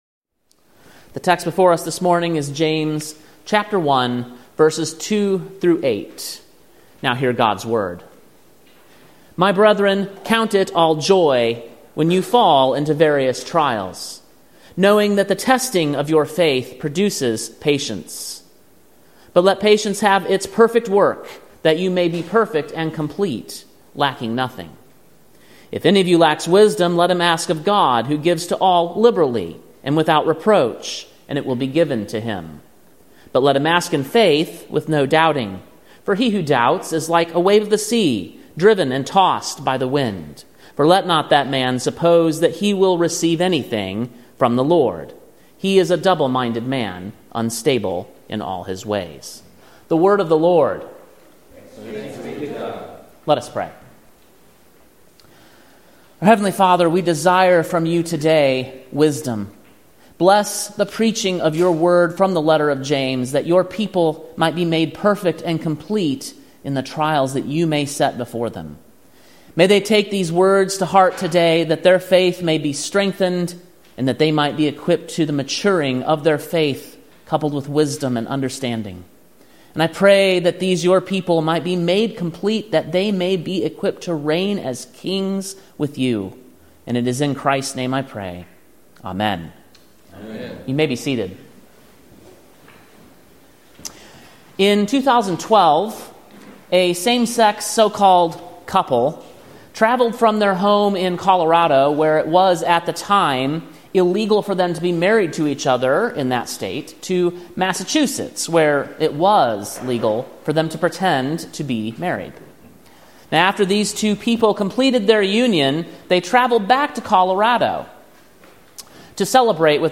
Sermon preached on May 26, 2024, at King’s Cross Reformed, Columbia, TN.